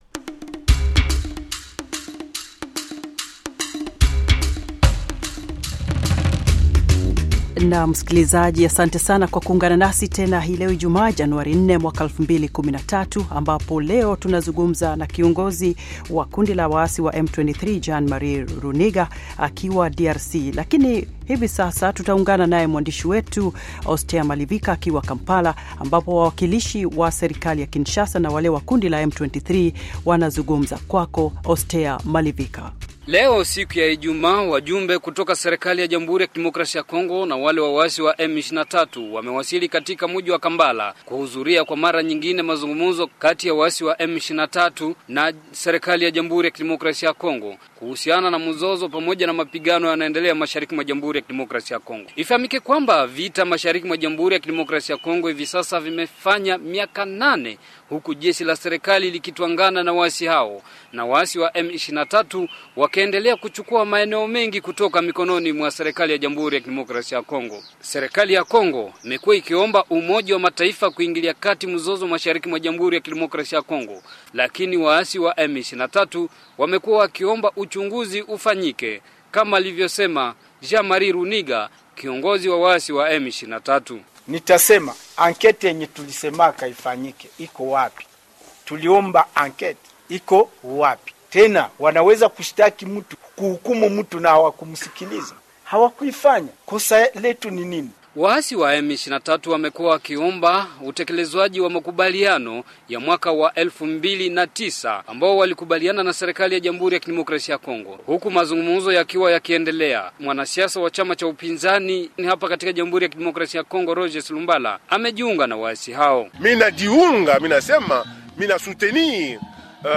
Kiongozi wa waasi wa M23 azungumza na VOA